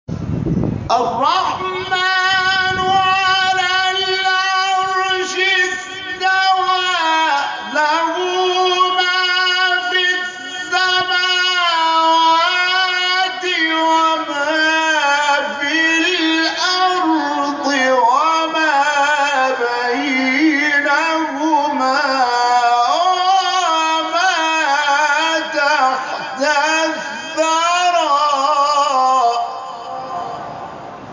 شبکه اجتماعی: نغمات صوتی از تلاوت قاریان برجسته و ممتاز کشور که به‌تازگی در شبکه‌های اجتماعی منتشر شده است، می‌شنوید.